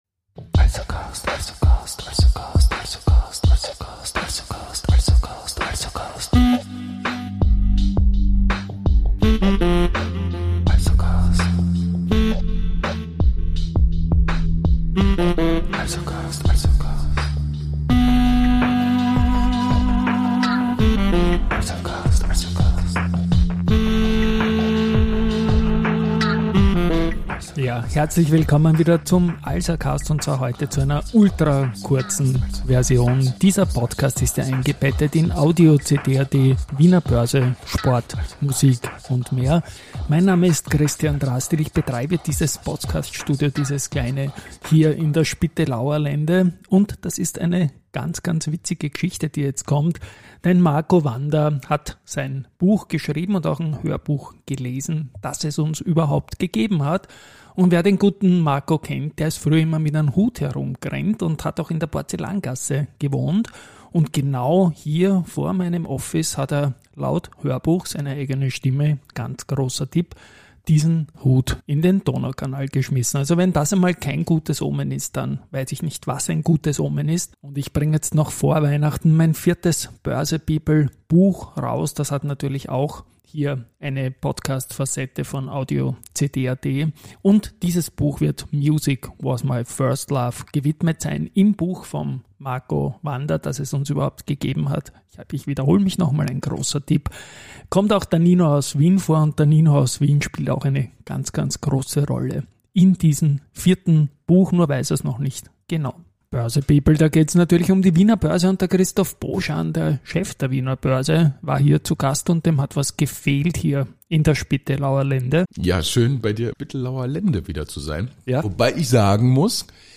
Der Alsercast #19 ist musiklastig: Zu Marco Wanda (in 1090) sowie auch Nino aus Wien (in meinem Heimatbezirk 1220) gibt es zufällige Bezugspunkte.